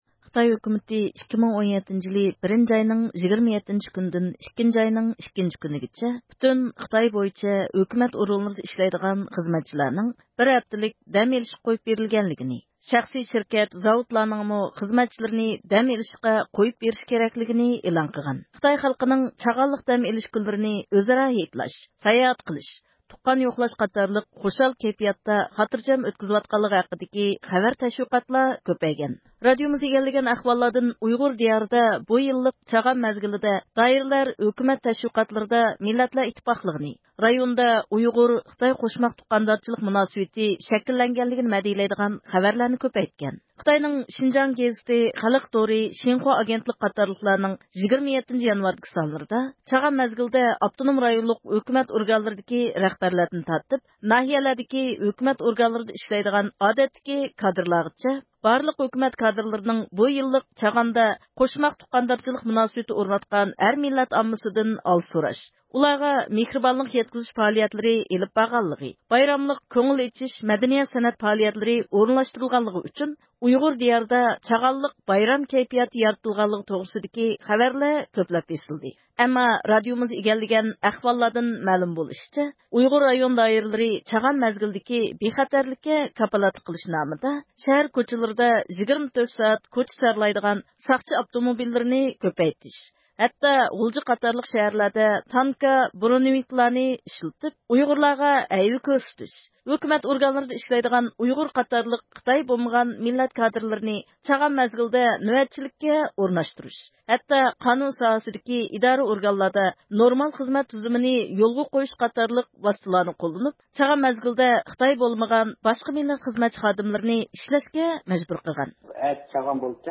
ئەركىن ئاسىيا رادىئوسى  مۇخبىرى ئۈرۈمچىدىكى مەلۇم ساقچى پونكىتىغا تېلېفون قىلغاندا، تېلېفوننى كېچىلىك نۆۋەتچىلىكتە تۇرۇۋاتقان ئۇيغۇر ساقچى ئالغان.
تېلېفوننى ئۇيقۇلۇق ھالدا ئالغان بۇ ساقچى خادىمى چاغان مەزگىلىدە ئۇيغۇر ساقچىلارنىڭ 24 ساتەتلىك نۆۋەتچىلىككە ئورۇنلاشتۇرۇلغانلىقىنى بىلدۈرۈپ، ئۆزىنىڭ قاتتىق چارچىغانلىقى ئۈچۈن، ئەگەر مەلۇم قىلىدىغان جىددىي ئەھۋال بولمىسا، نورمال خىزمەت ۋاقتىدا تېلېفون قىلىشىنى ئېيتىپ تېلېفوننى قويۇۋەتكەن.